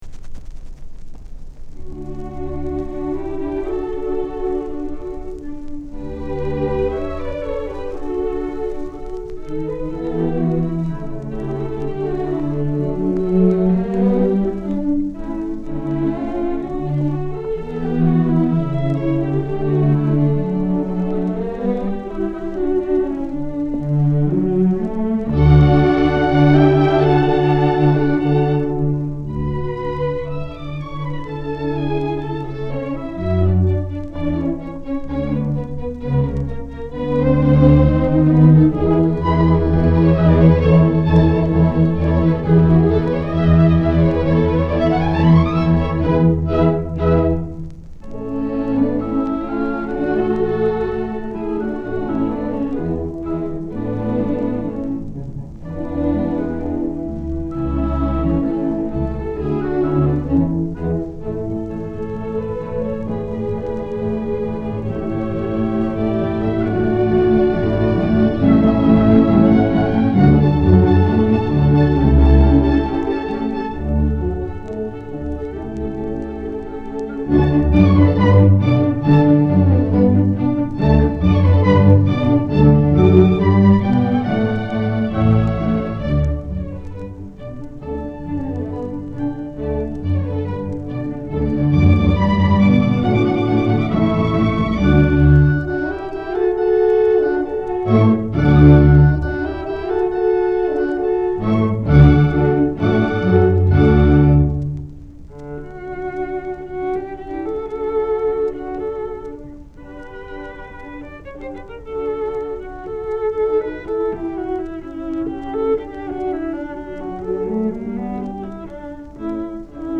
Vintage DIY audio articles & audiofile shellac and vinyl remastering